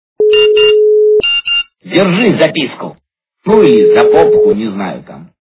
» Звуки » звуки для СМС » Для СМС - Держи записку...
При прослушивании Для СМС - Держи записку... качество понижено и присутствуют гудки.